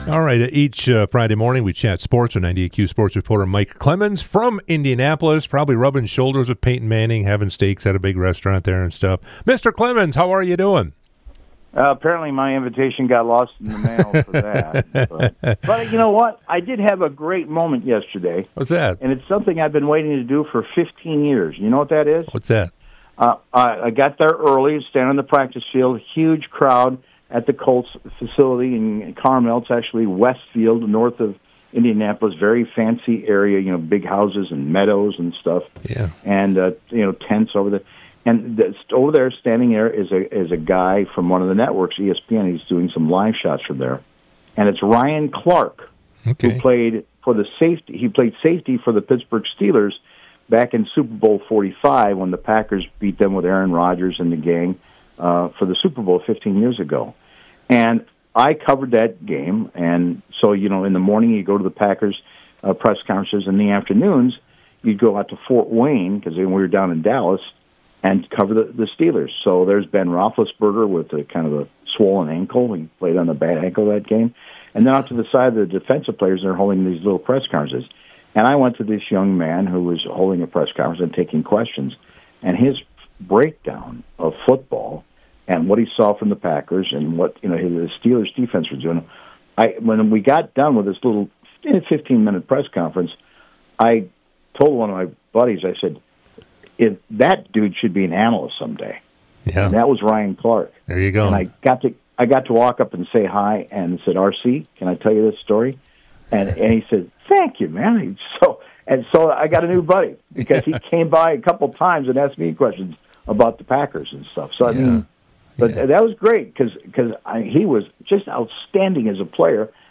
98q interviews